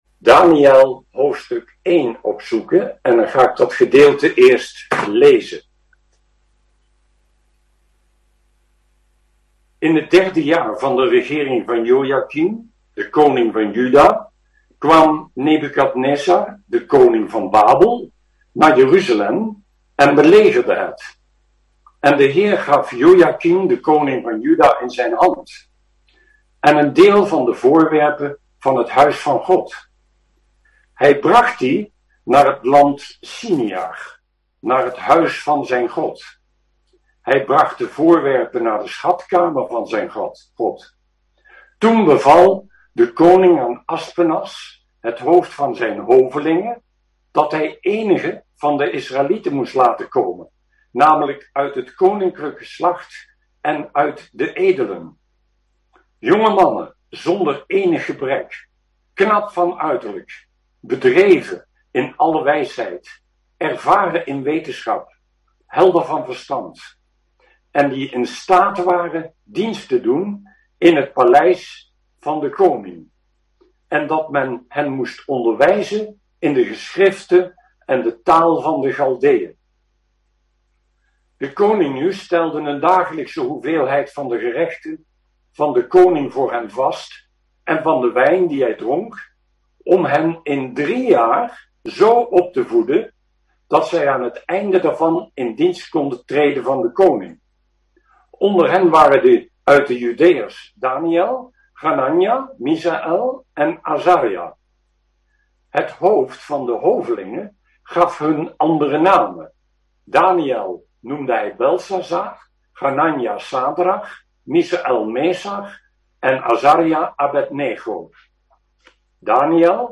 De audio is afkomstig van een webcamopname, vandaar de mindere geluidskwaliteit.